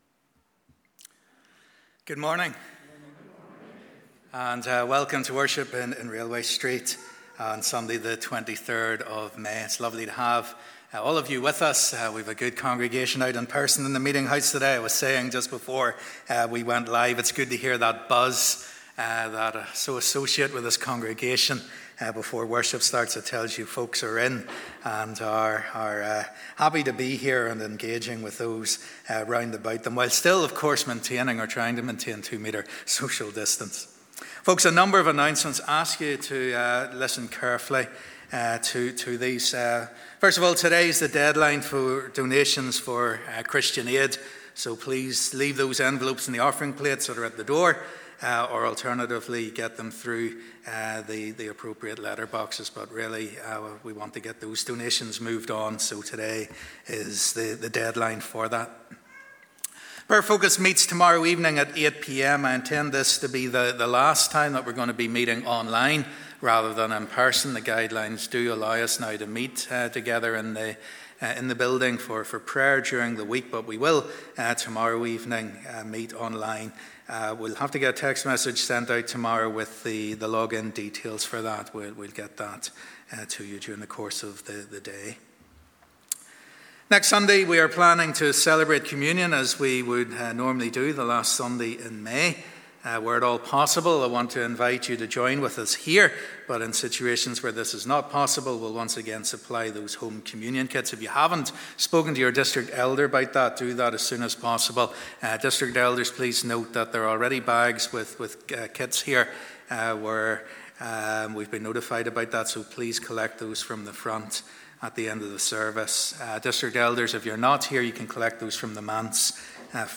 Audio will be available from 1pm following the service.